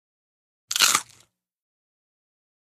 CrispySnackSnglCru PE678002
DINING - KITCHENS & EATING CRISPY SNACK: INT: Single crunch.